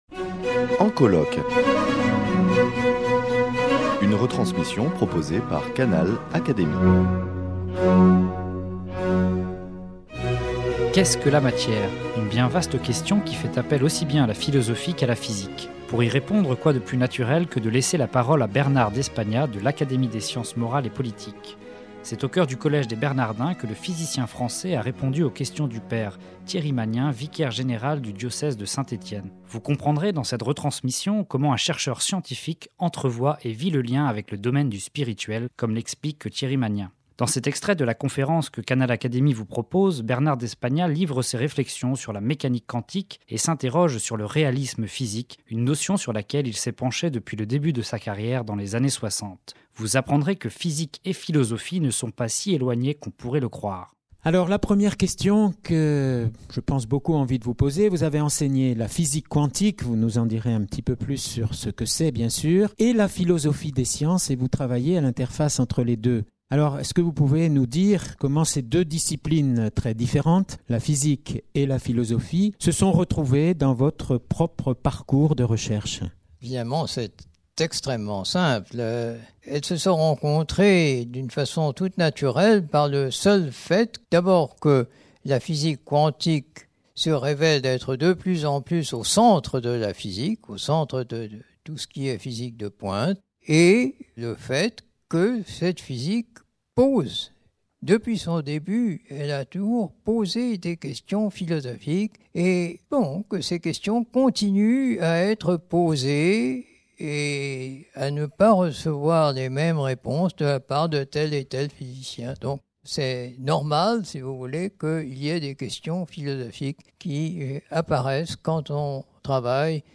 Le Collège des Bernardins à Paris a proposé une soirée-débat avec Bernard d’Espagnat de l’Académie des sciences morales et politiques.